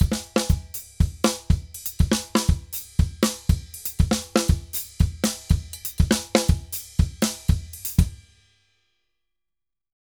Drums_Merengue 120_4.wav